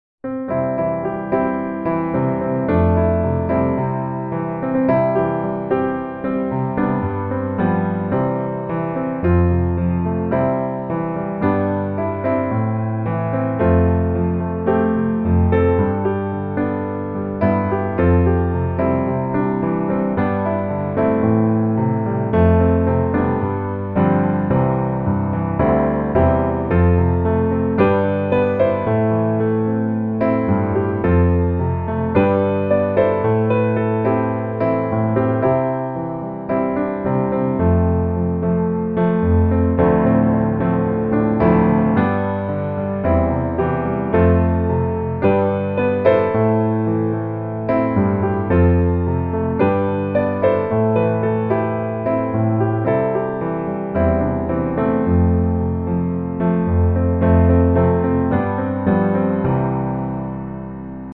C Major